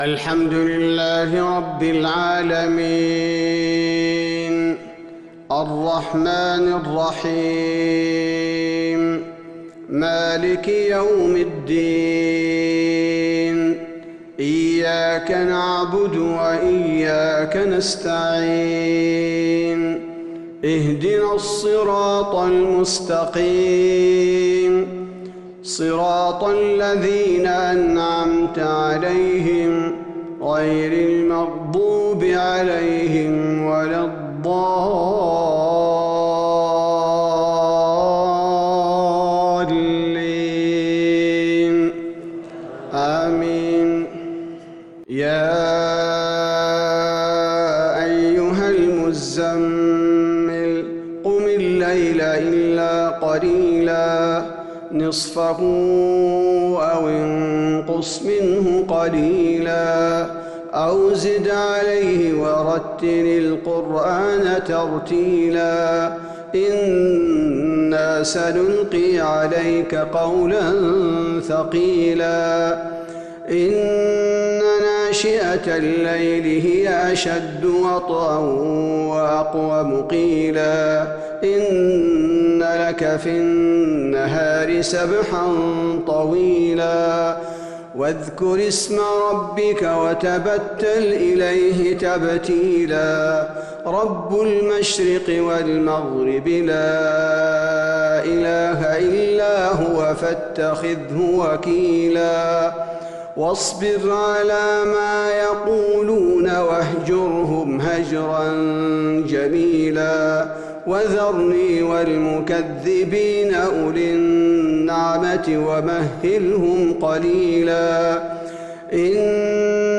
صلاة العشاء للقارئ عبدالباري الثبيتي 7 ربيع الأول 1442 هـ
تِلَاوَات الْحَرَمَيْن .